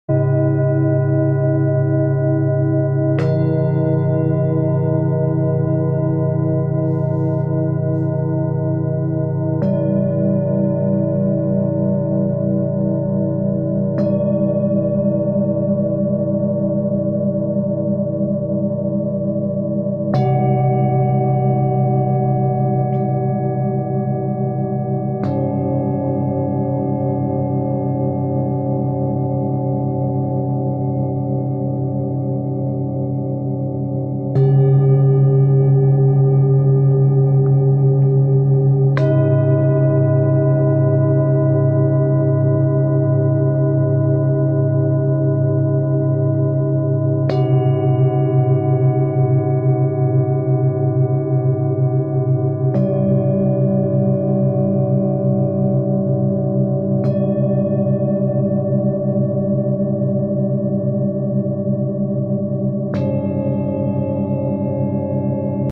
Sound Bath Recording
Let targeted frequencies help with your sleep.